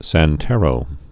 (săn-tĕrō, sän-)